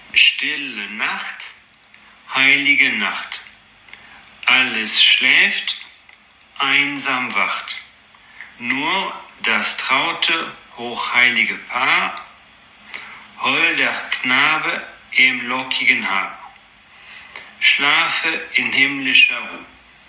Pour écouter la prononciation